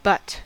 Ääntäminen
UK : IPA : /bʌt/ stressed: IPA : /bʌt/ US : IPA : /bʌt/